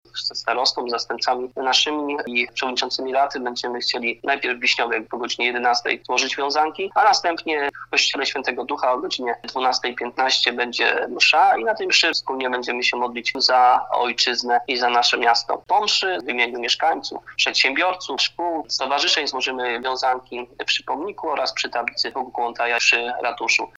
Mówi burmistrz Staszowa Leszek Kopeć.